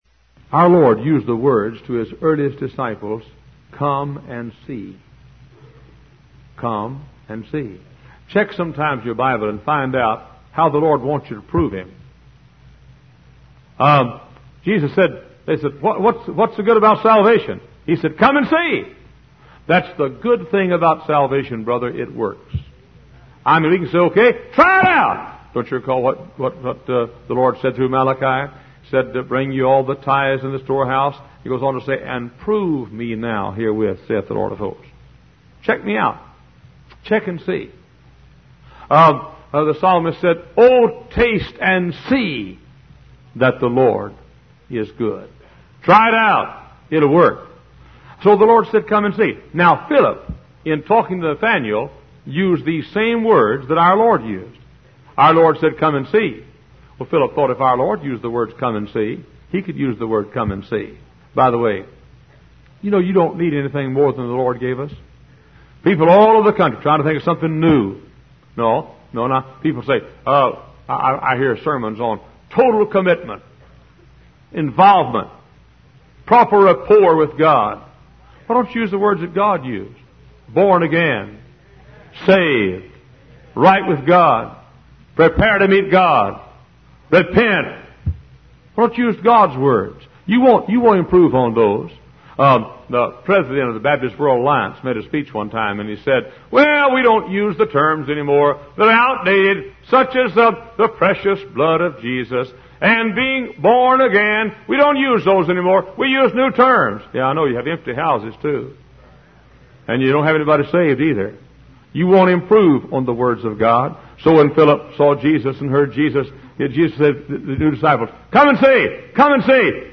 Professing themselves to be wise, they became fools. Wise Desire Ministries helps convey various Christian videos and audio sermons.